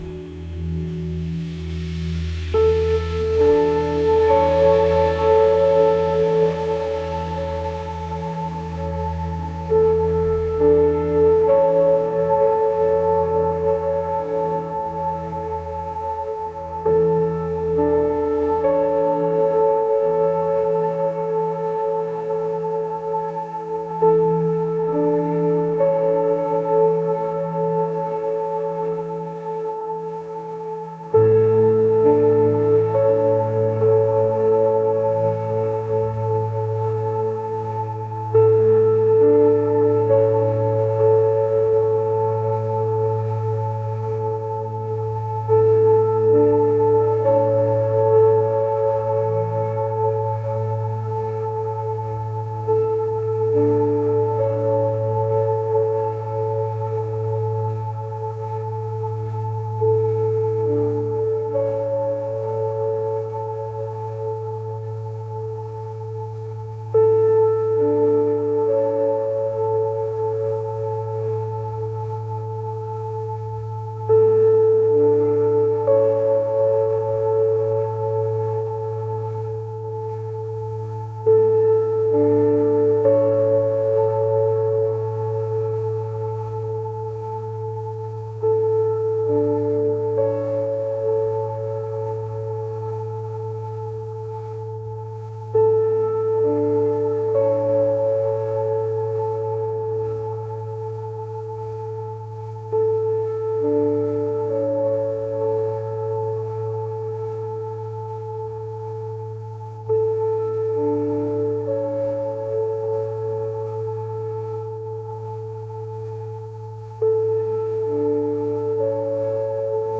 pop | atmospheric | ethereal